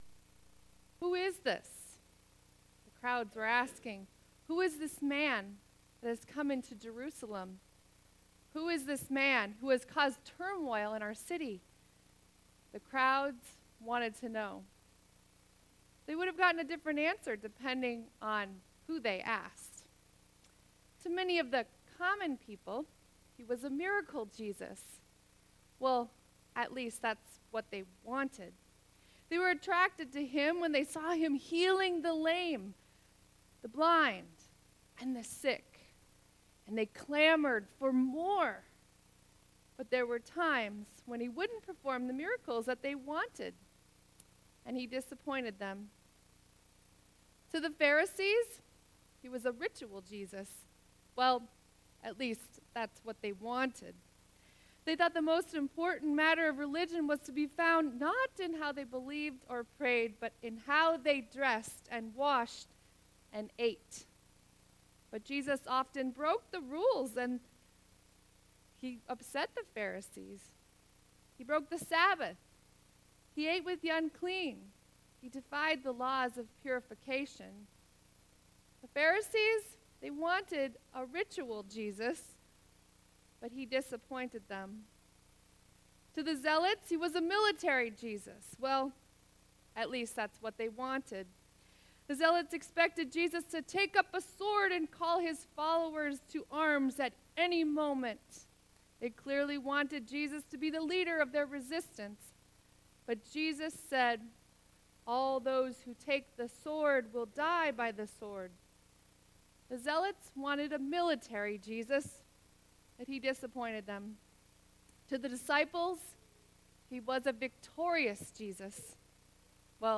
Sermon 3.25.2018